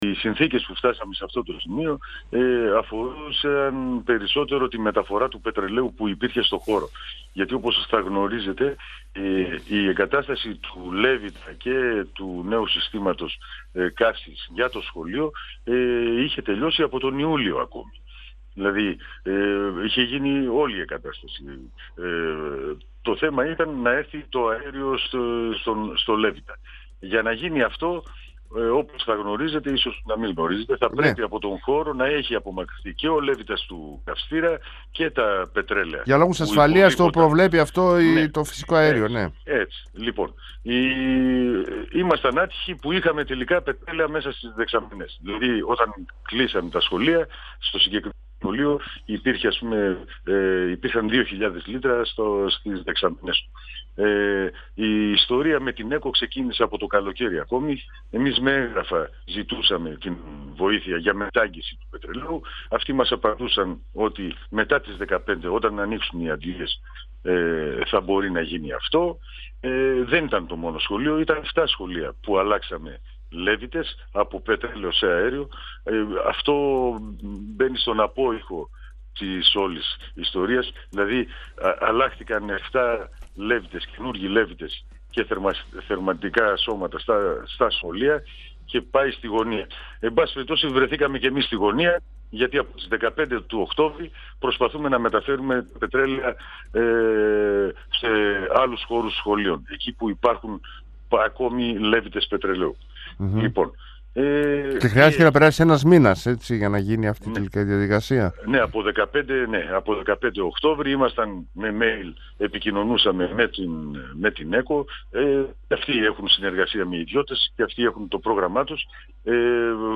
Ο αντιδήμαρχος παιδείας Πολίχνης, Ιωσήφ Αράπογλου, στον 102FM του Ρ.Σ.Μ. της ΕΡΤ3
Συνέντευξη